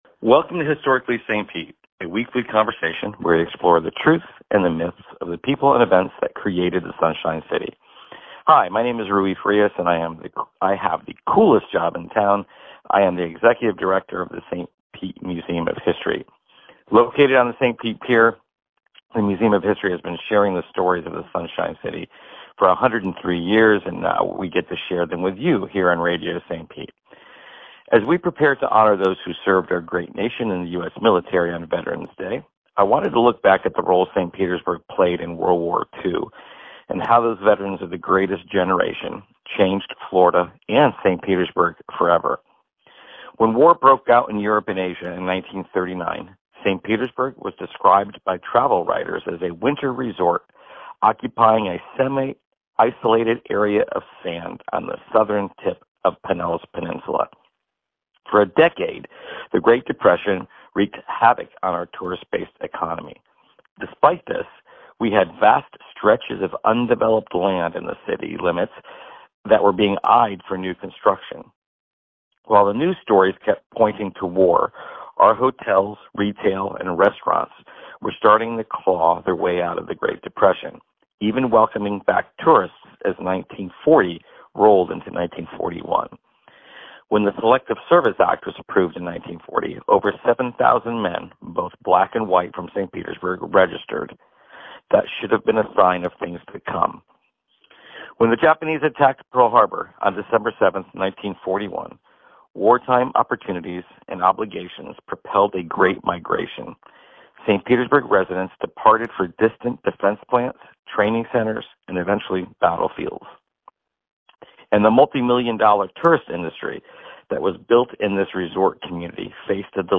Welcome to Historically St Pete, a weekly conversation where we explore the